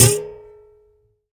stringSnap.ogg